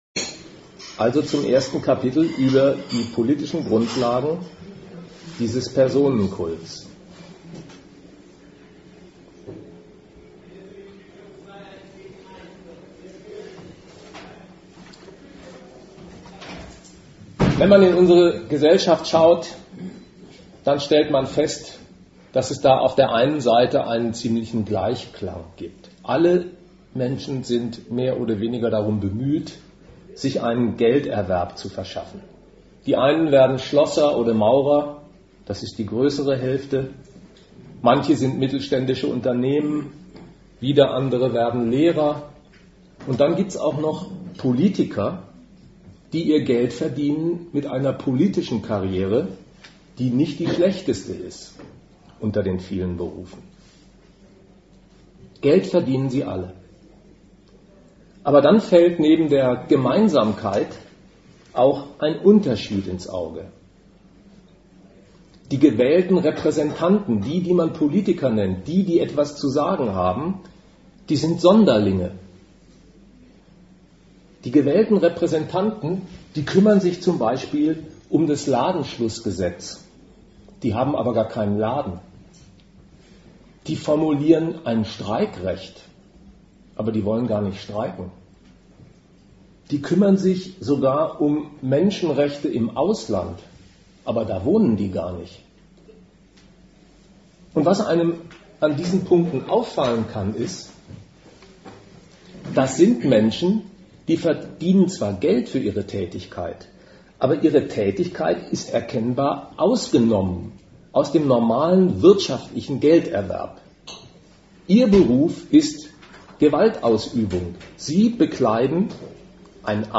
Ort Regensburg
Dozent Ein Redakteur des GegenStandpunkt-Verlages